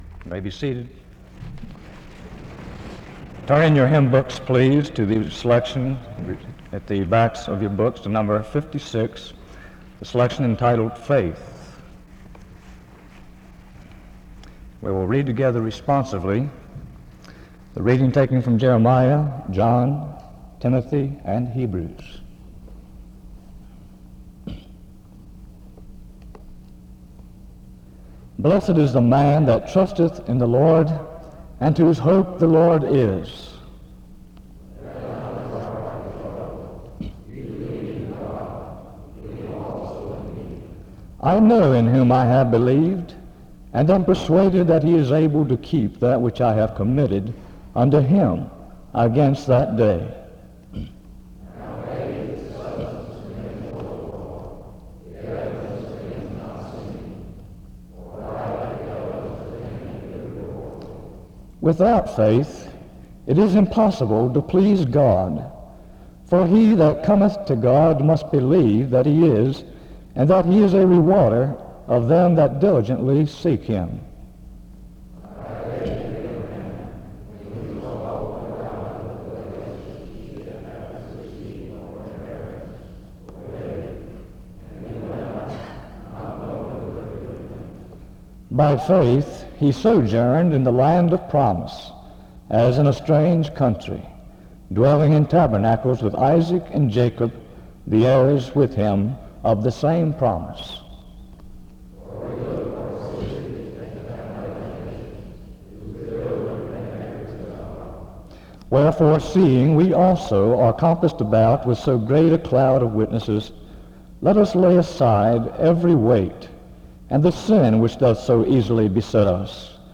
SEBTS_Chapel_Student_Service_1968-05-16.wav